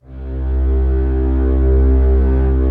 Index of /90_sSampleCDs/Optical Media International - Sonic Images Library/SI1_Swell String/SI1_Slow Swell
SI1 SWELL02L.wav